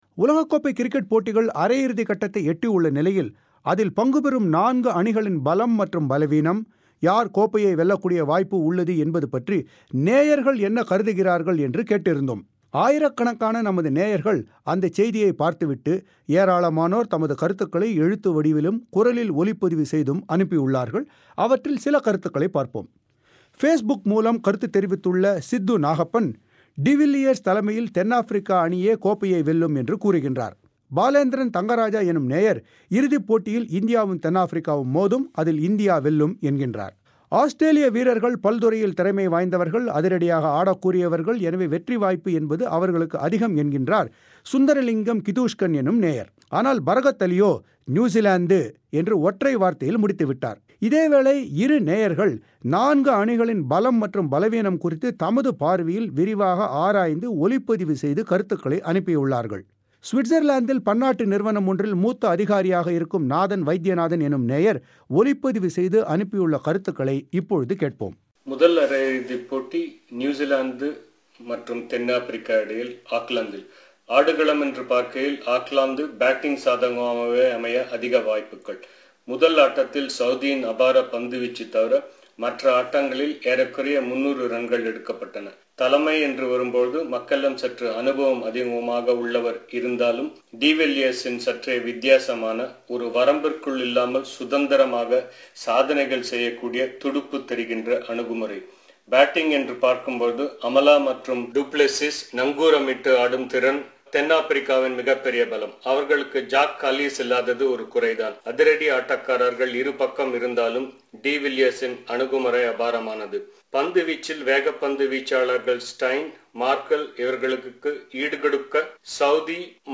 ஆயிரக்கணக்கான நமது நேயர்கள் அந்தச் செய்தியைப் பார்த்துவிட்டு ஏராளமானோர் தமது கருத்துக்களை எழுத்து வடிவிலும், குரலில் ஒலிப்பதிவு செய்தும் அனுப்பியுள்ளனர்.